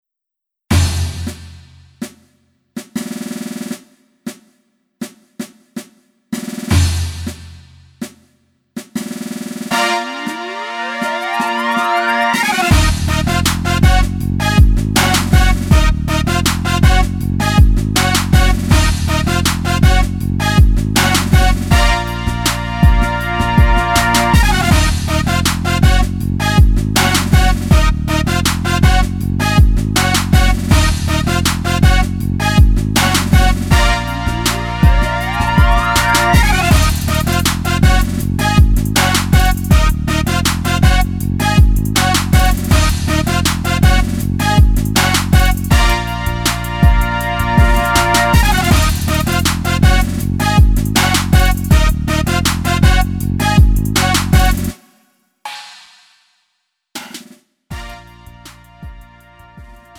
음정 원키 3:57
장르 가요 구분 Lite MR